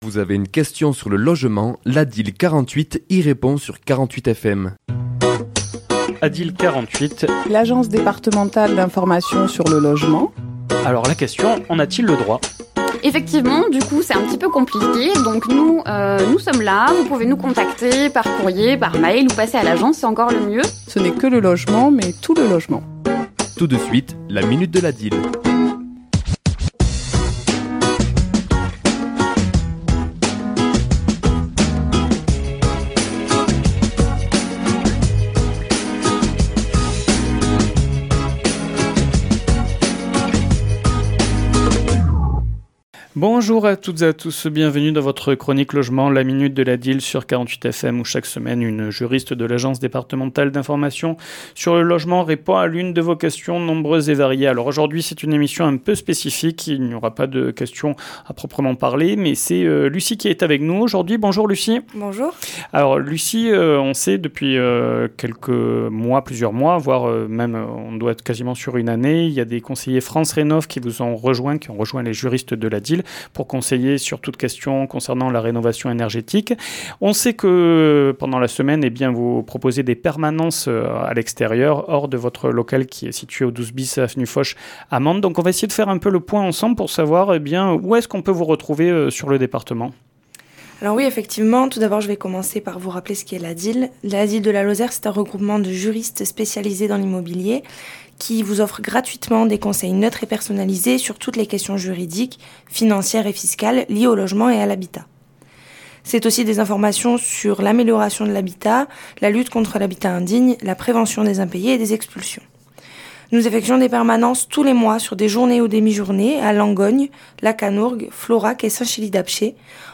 Chronique diffusée le mardi 13 mai à 11h et 17h10